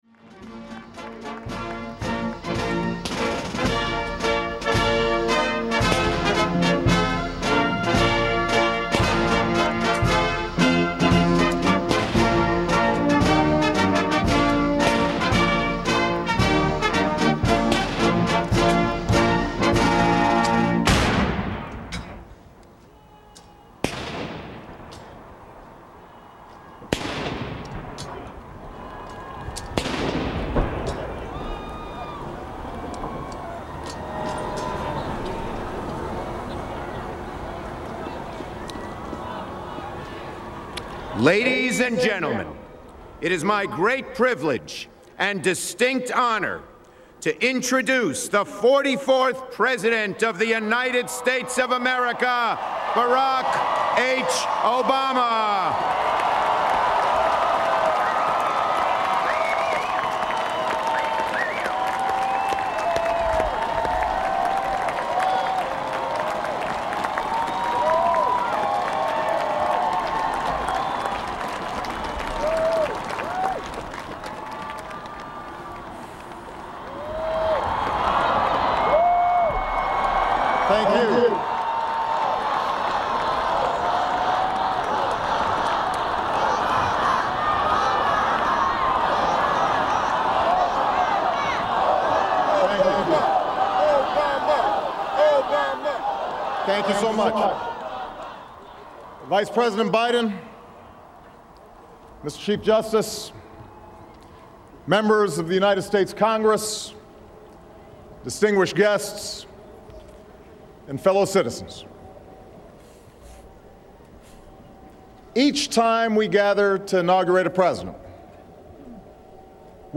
U.S. President Barack Obama delivers his second inaugural speech during the 57th Inaugural Ceremonies in Washington, D.C